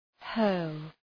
Προφορά
{hɜ:rl}